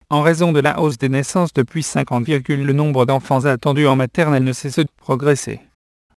Texte de d�monstration lu par Alain (AT&T Natural Voices; distribu� sur le site de Nextup Technology; homme; fran�ais)